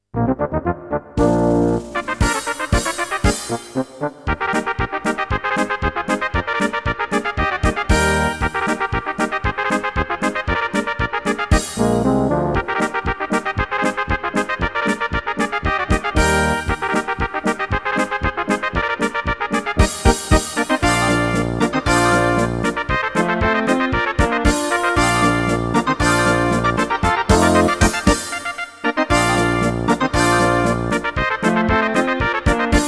Rubrika: Národní, lidové, dechovka
- polka